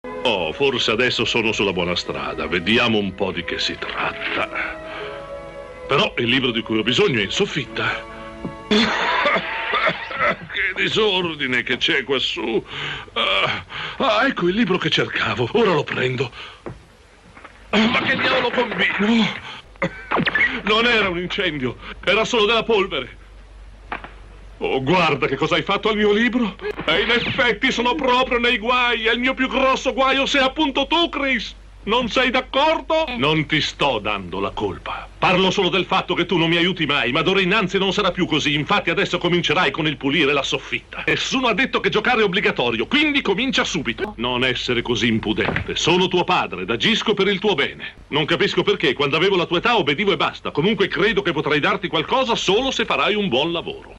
nel cartone animato "Superbook", in cui doppia il prof. Fred Peeper.